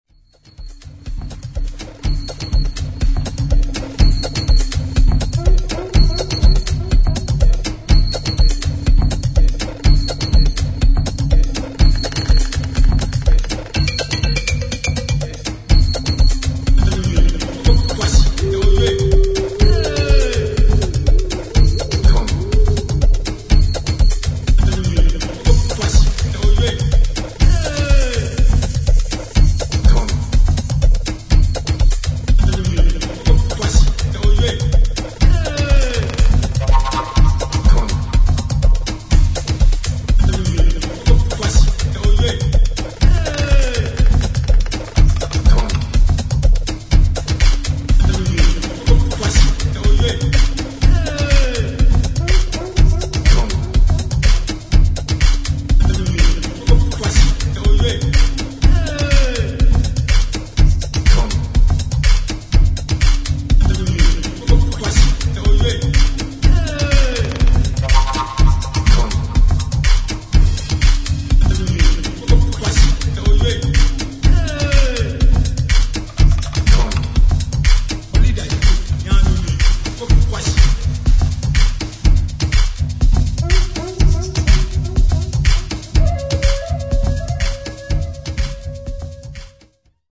[ TECHNO / ELECTRONIC ]